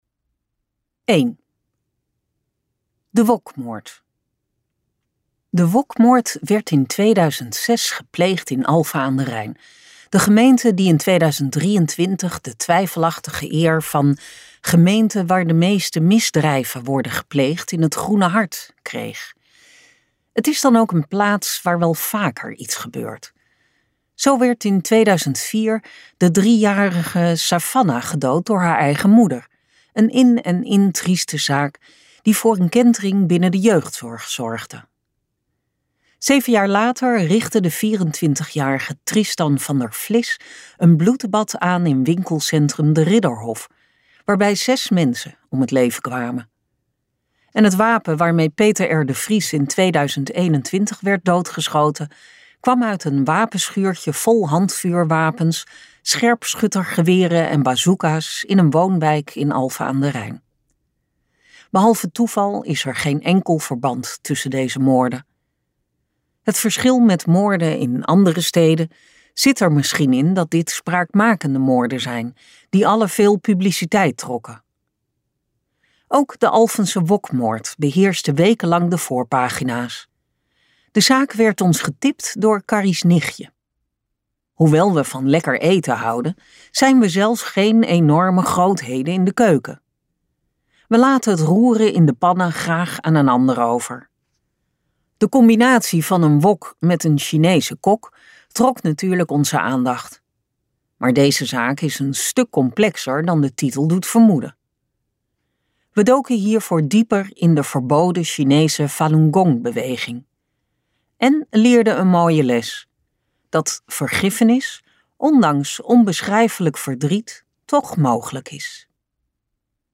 Ambo|Anthos uitgevers - De pindakaasmoord luisterboek